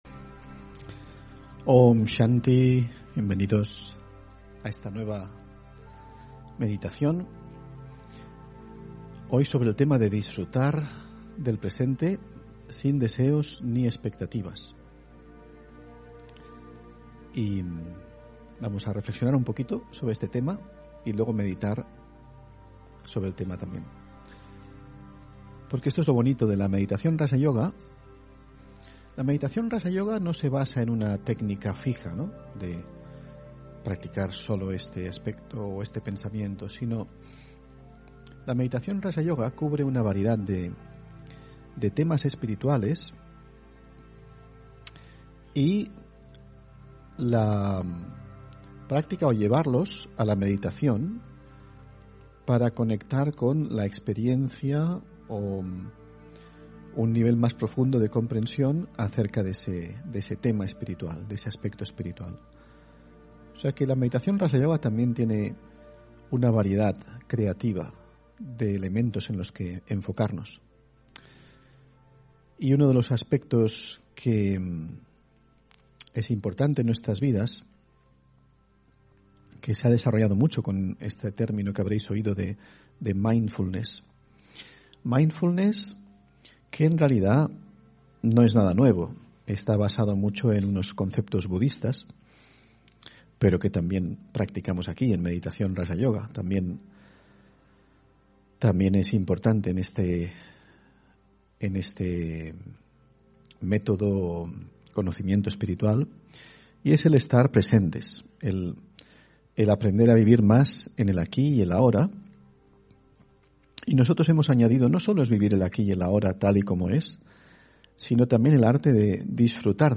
Meditación y conferencia: Disfrutar del presente sin deseos ni expectativas (1 Marzo 2022)